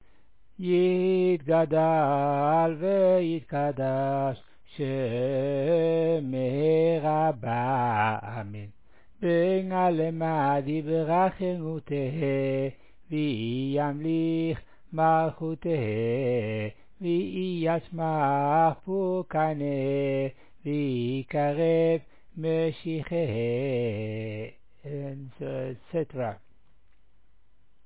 Recordings: NRP & JBS
Friday night the Kaddish is sung.
On this Shabbat the melody of מי כמוך is used for the
Kaddish and יגדל Friday night, ה' מלך during Zemirot, and אין כאלקנו and אדון עולם after Musaf.